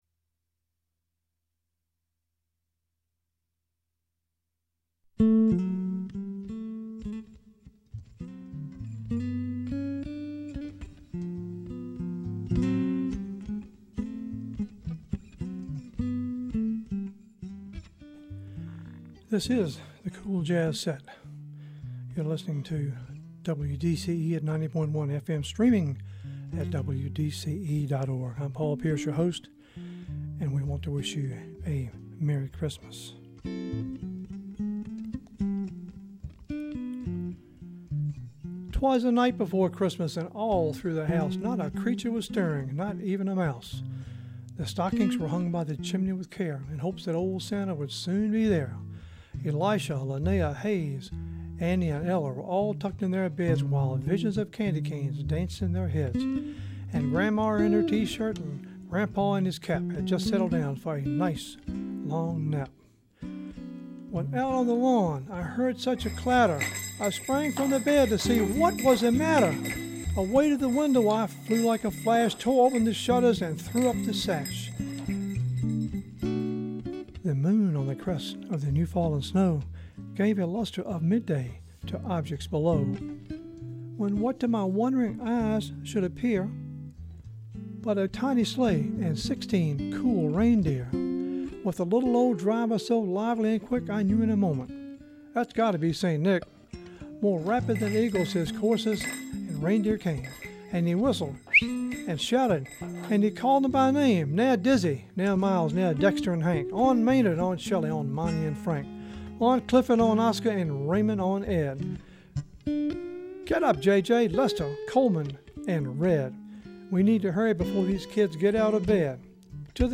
Swinging into Christmas; 12/20/15; Set 2 Subtitle: cool jazz set Program Type: Unspecified Speakers: Version: 1 Version Description: Version Length: 1 a.m. Date Recorded: Dec. 20, 2015 1: 1 a.m. - 56MB download